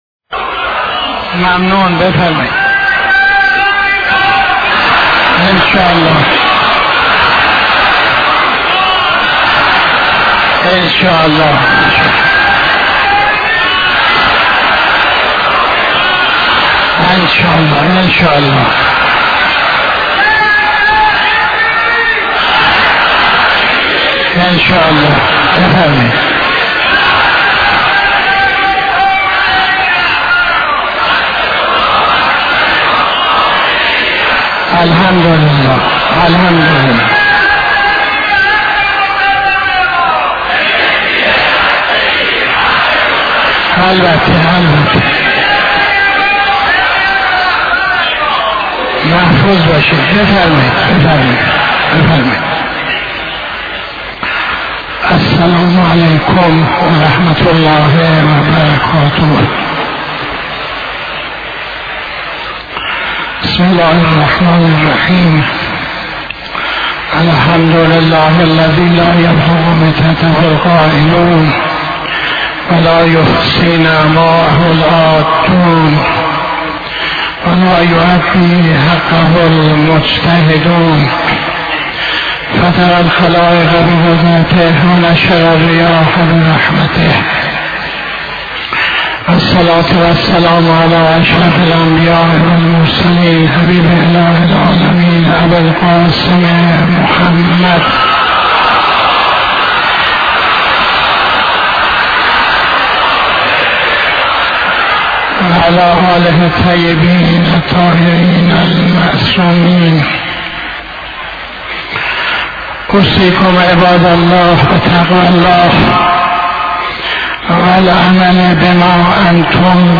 خطبه اول نماز جمعه 19-01-84